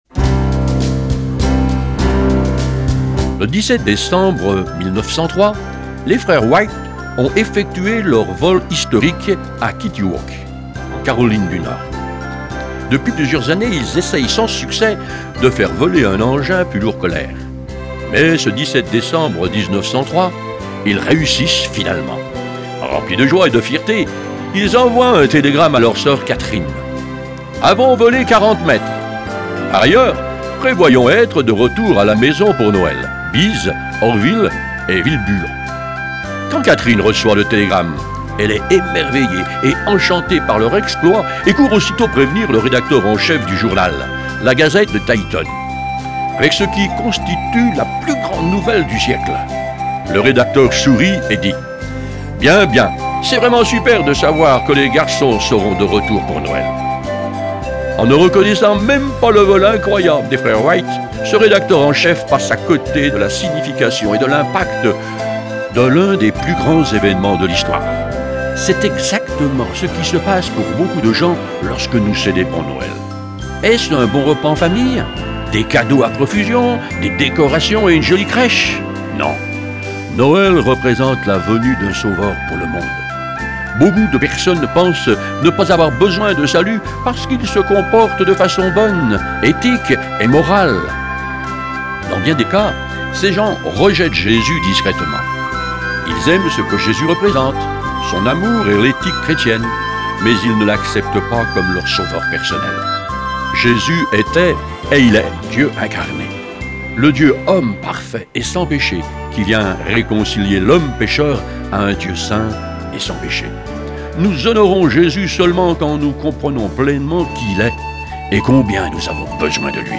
Une série de méditations pour le mois de Décembre
Version audio Phare FM :